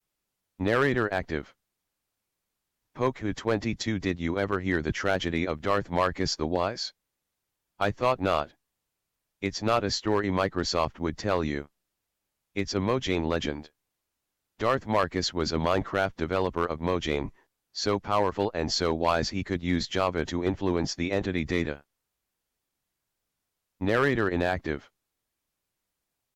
Narrator reads scoreboard team color codes in player names
WithoutColor.mp3